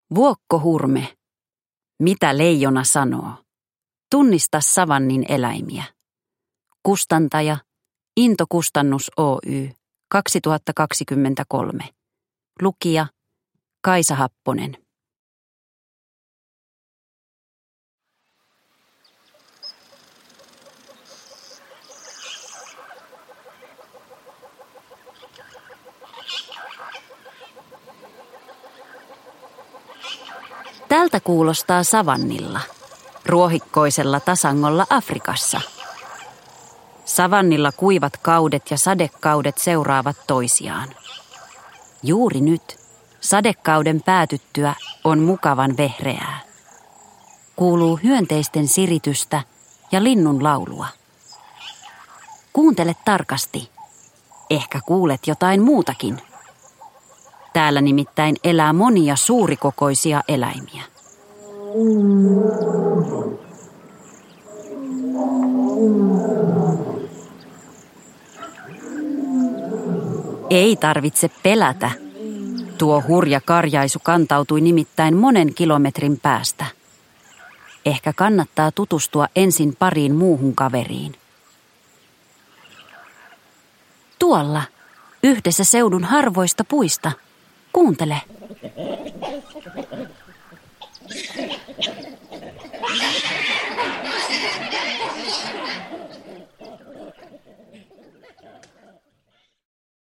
Ljudbok
Mitä leijona sanoo -äänikirja vie kuulijan savannille keskelle eksoottista äänimaailmaa.
Villit ja vinkeät eläinäänet, metsän tunnelmalliset suhinat ja monenmoiset rapinat vievät lapsen suoraan tapahtumapaikoille.